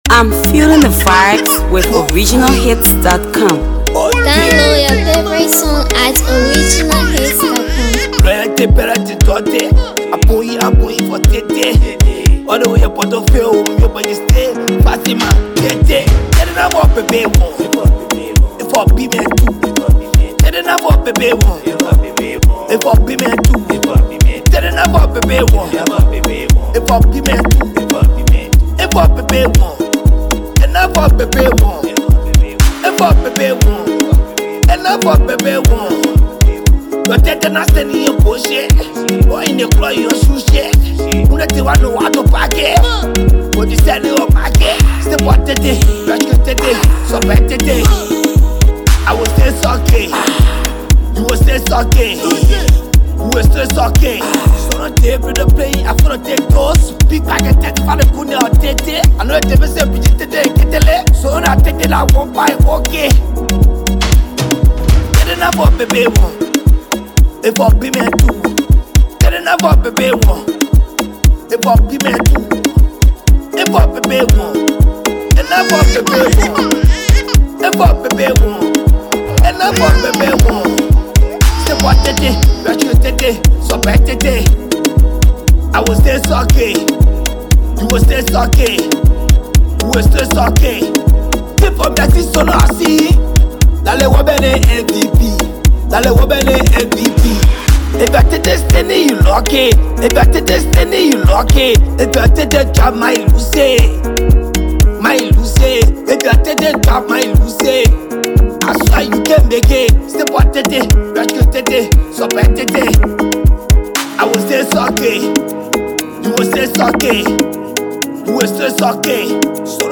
explicit lyrics banger
Afro Pop